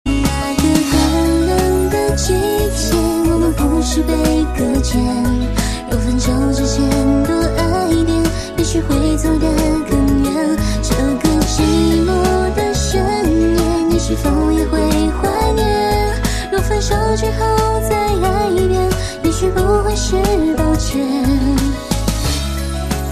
M4R铃声, MP3铃声, 华语歌曲 112 首发日期：2018-05-14 20:01 星期一